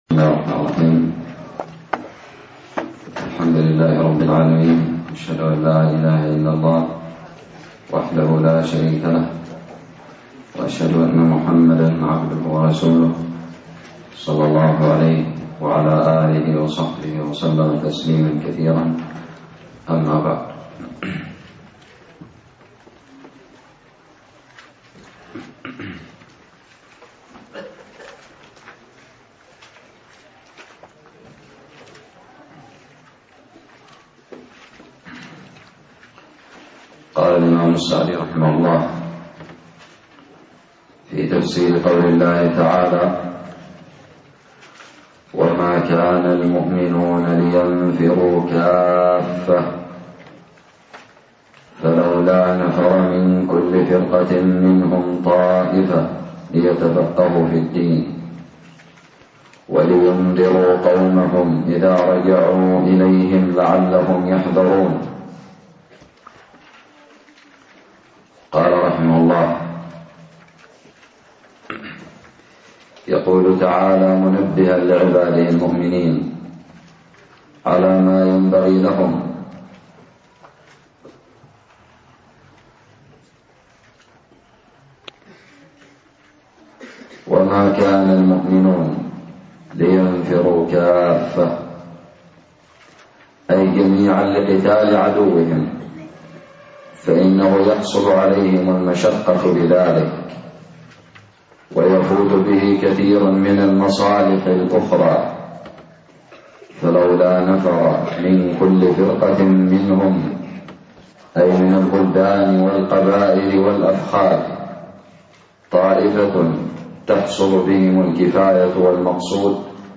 الدرس الثالث والخمسون من تفسير سورة التوبة
ألقيت بدار الحديث السلفية للعلوم الشرعية بالضالع